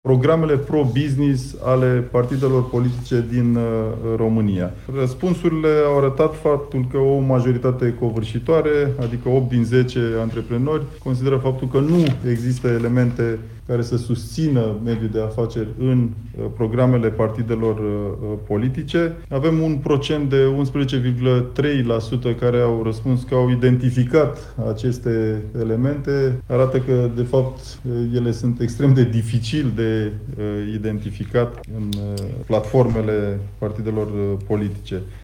Președintele IMM România, Florin Jianu: „Opt din zece antreprenori consideră faptul că nu există elemente care să susțină mediul de afaceri în programele partidelor politice”